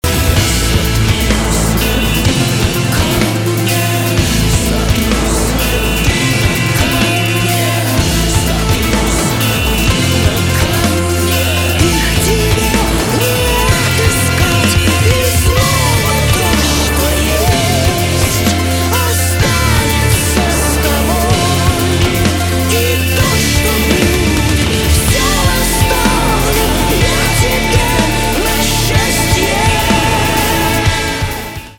русский рок , гитара , барабаны
грустные